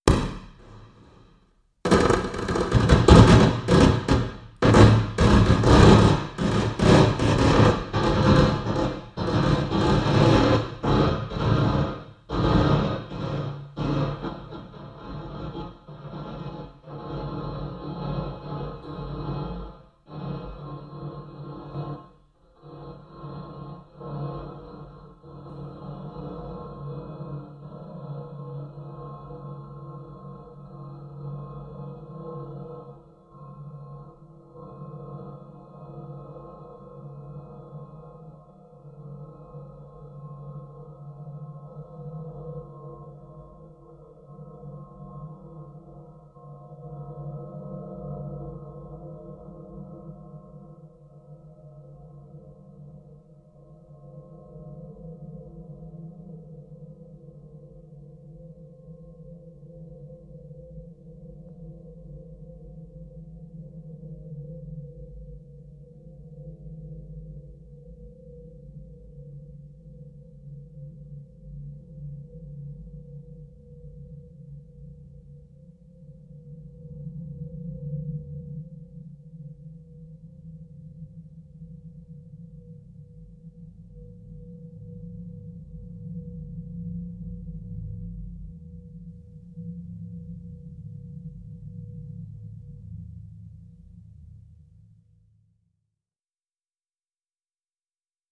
impulsantwort.wav